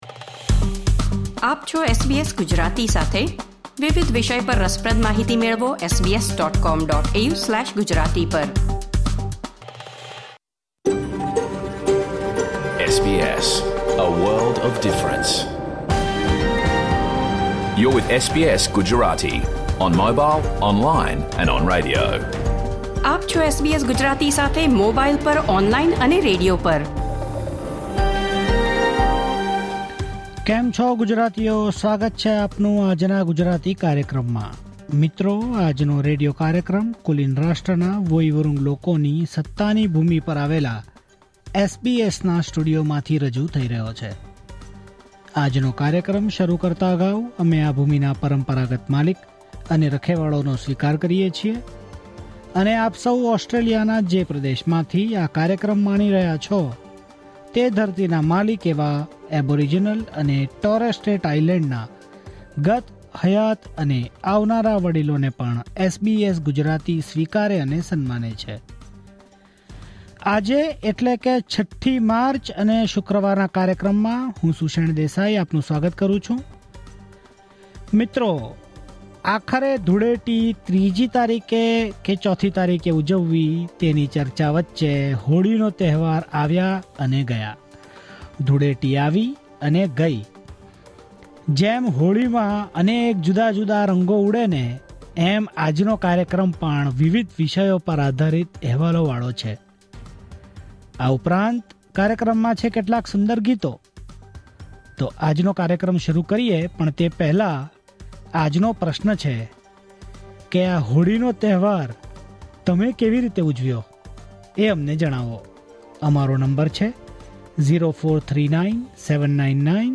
Listen to the full SBS Gujarati radio program